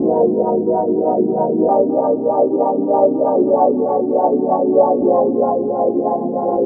K-6 Pad 1 LFO.wav